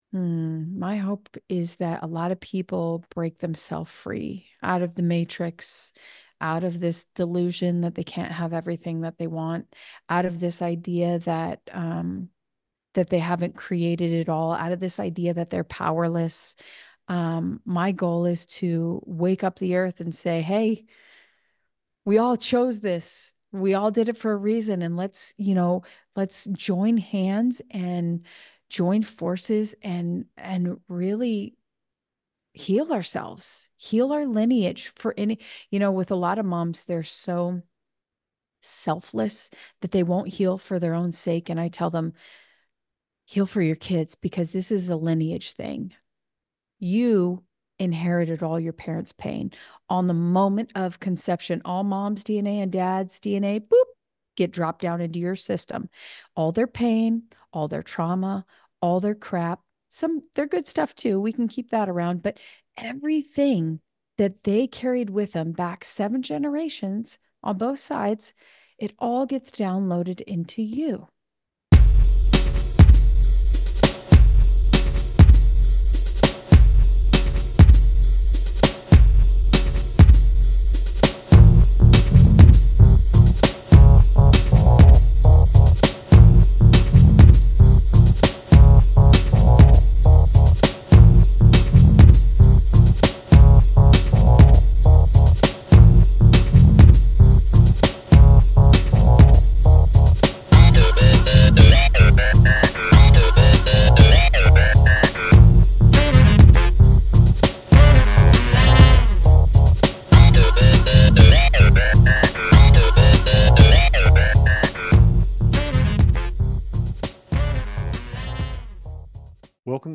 Interview How Can You Transform Trauma To Fulfill Your Life Mission?